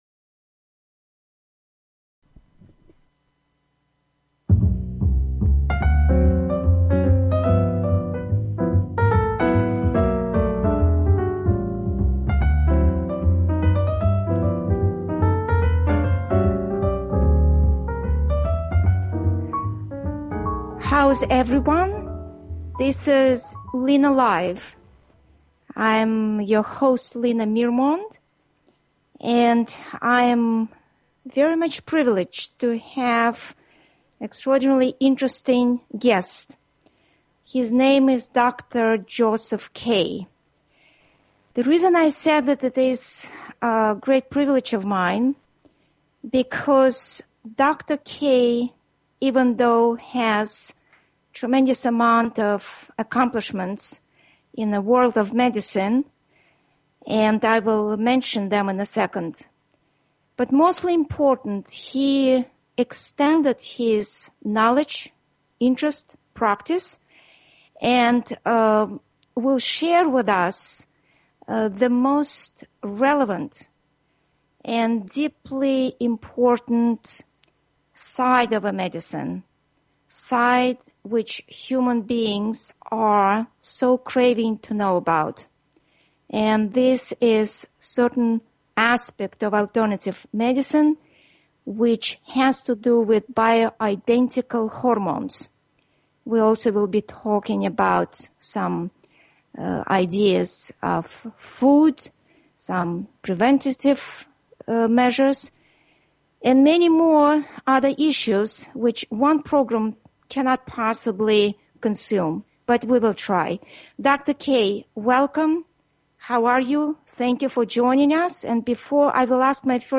Piano Music
Talk Show